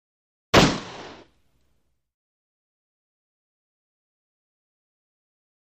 9 mm UZI 1; Single Shot.